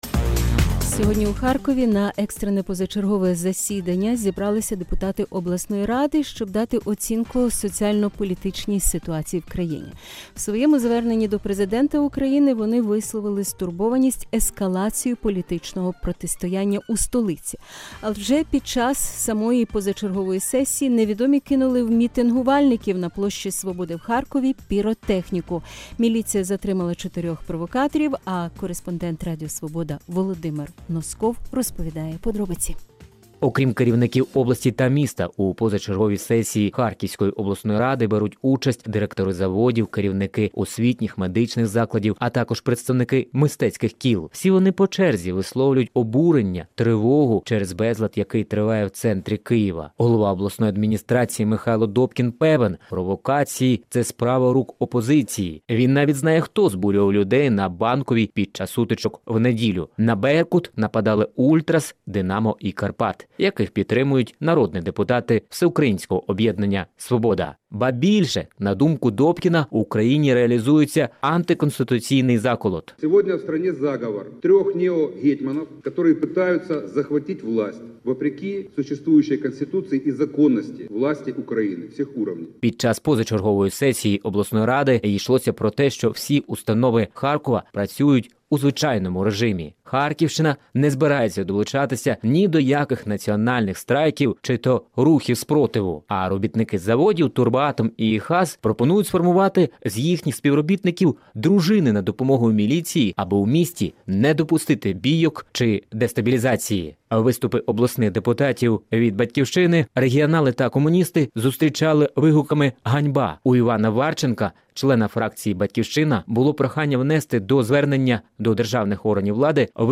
Про стабільність у Харкові під вибухи петард на пікеті